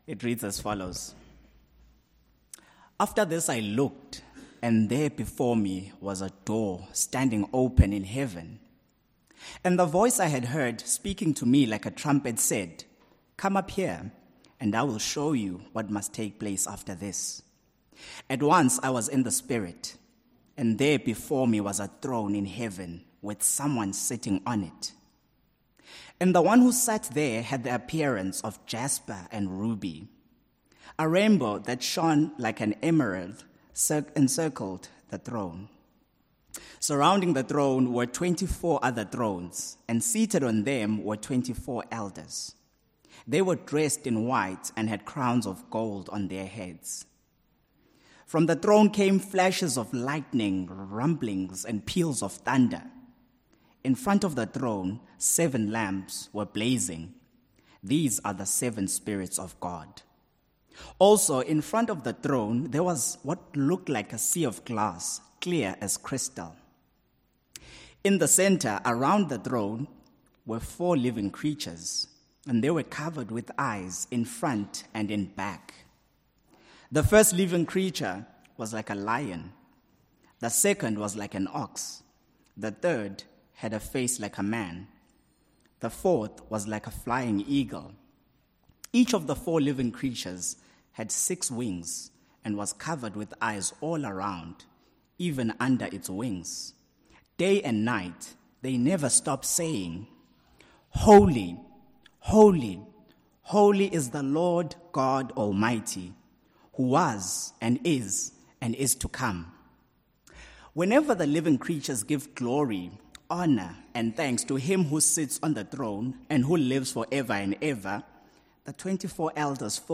Revelation Passage: Revelation 4:1-11 & 5:1-14 Service Type: Morning Service « The King’s Speech